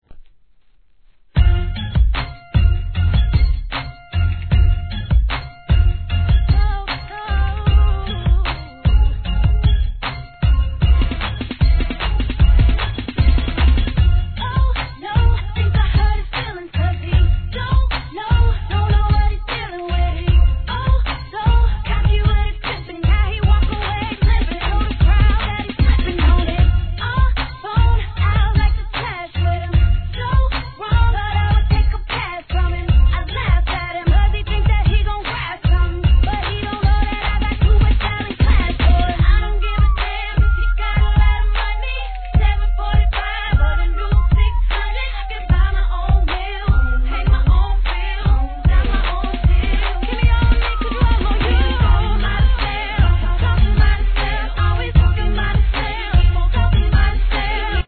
HIP HOP/R&B
SOUTHサウンドに耳当りの良いコーラスで聴かせる安心ミディアム・ナンバー!!